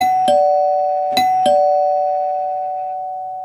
Türklingel klingelton kostenlos
Kategorien: Soundeffekte
turklingel-1.mp3